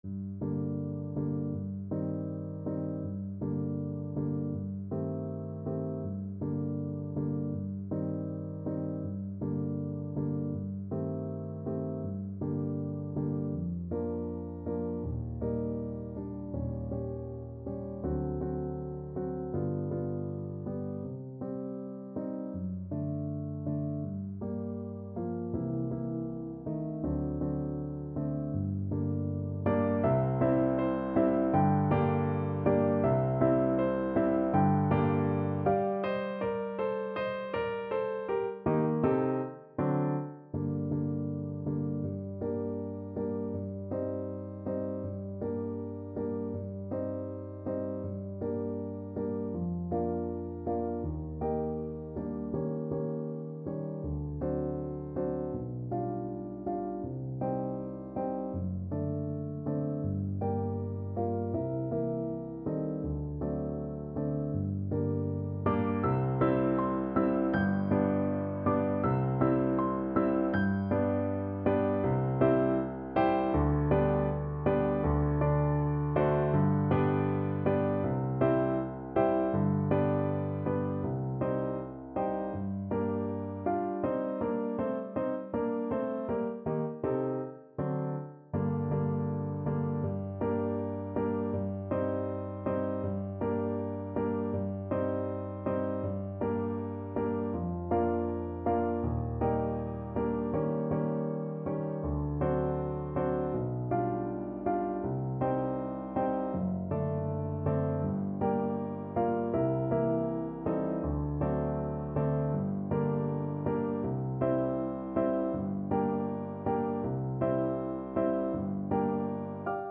Play (or use space bar on your keyboard) Pause Music Playalong - Piano Accompaniment Playalong Band Accompaniment not yet available reset tempo print settings full screen
French Horn
2/4 (View more 2/4 Music)
F#4-Bb5
~ = 100 Andante
Classical (View more Classical French Horn Music)